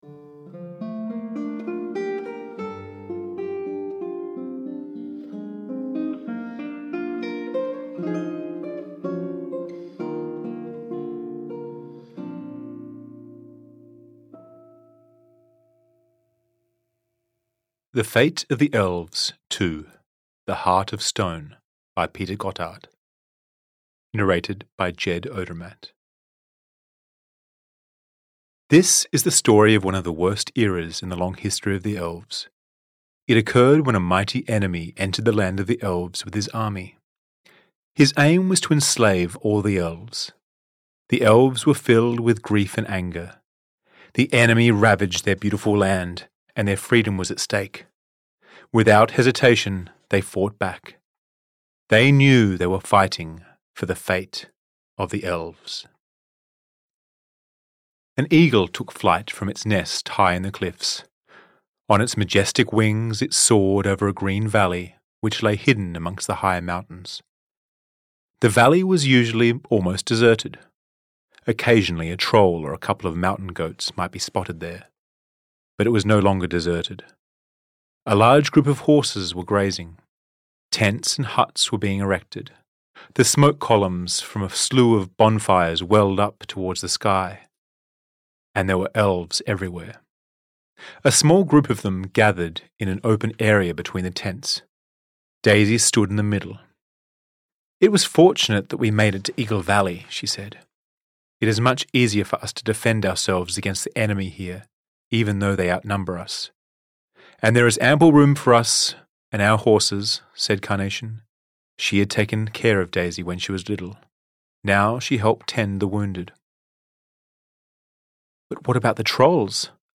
Audio knihaThe Fate of the Elves 2: The Heart of Stone (EN)
Ukázka z knihy